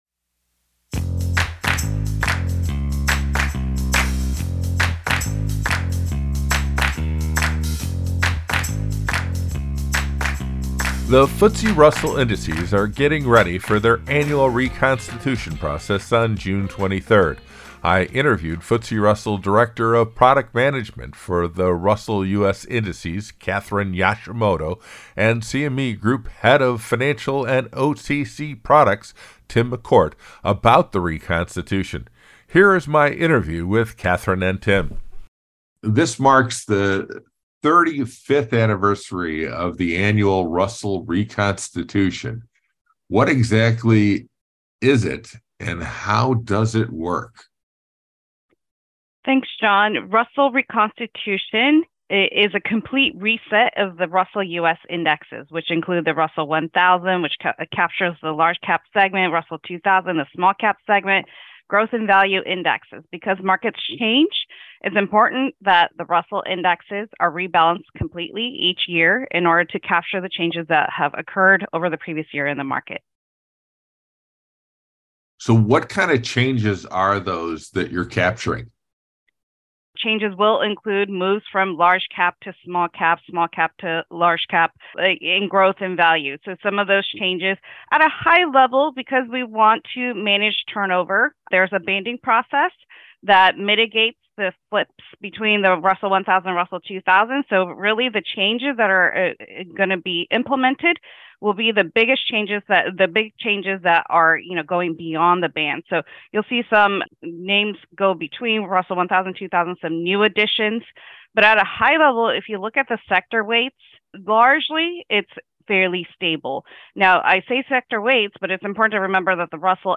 FTSE Russell Reconstitution Podcast Interview